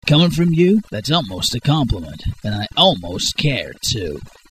Voice Description: We are looking for something medium-pitch, sly and scheming.